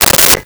Telephone Receiver Picked Up 01
Telephone Receiver Picked Up 01.wav